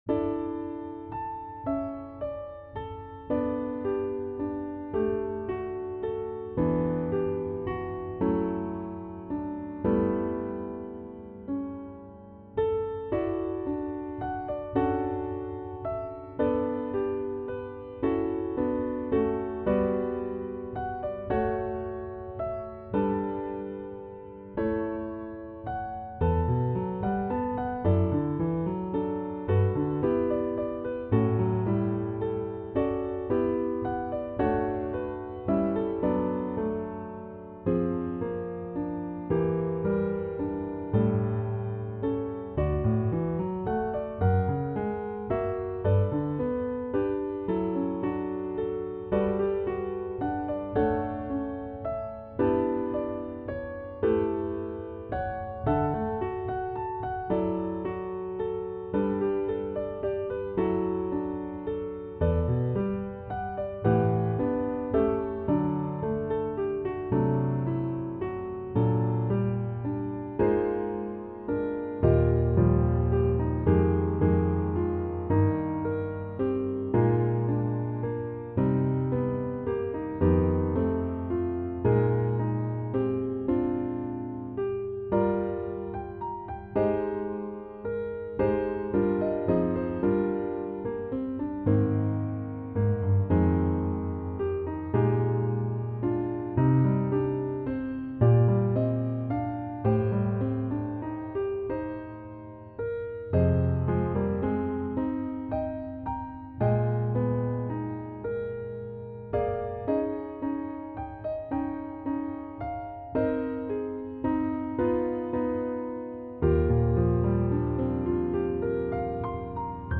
Piano Solo
Voicing/Instrumentation: Piano Solo We also have other 59 arrangements of " Amazing Grace ".